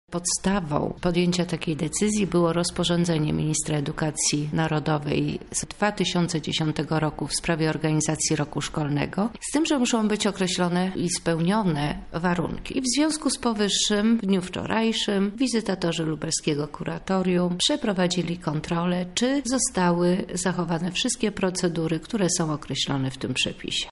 Opracowywany jest teraz materiał z kontroli – mówi Anna Szczepińska, Lubelski Wicekurator Oświaty.